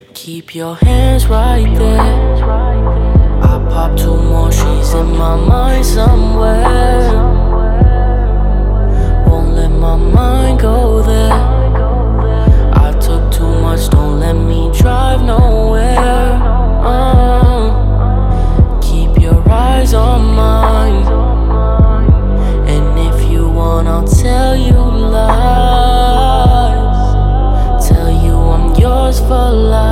• Качество: 320, Stereo
спокойные
медленные
красивый мужской вокал
Chill Trap
dark r&b